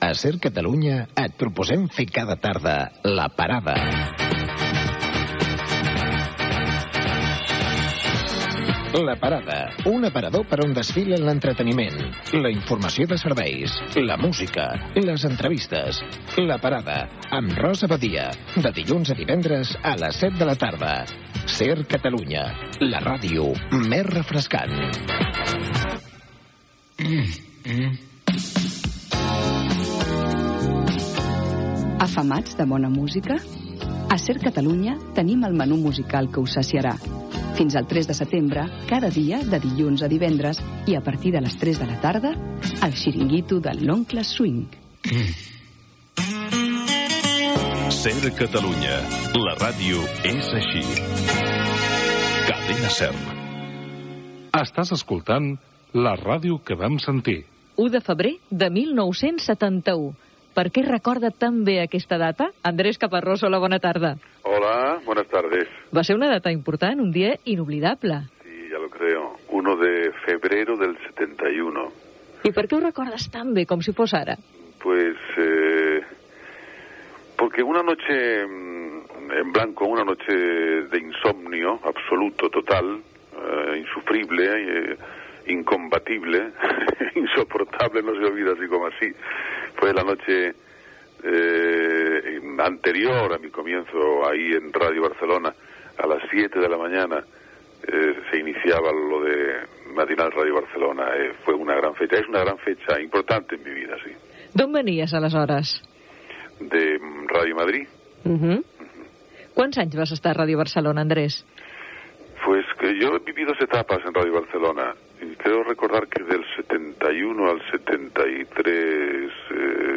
Promoció dels programes "La parada" i "El xiringuito de l'oncle Swing". Indicatiu de l'emissora i del programa.
FM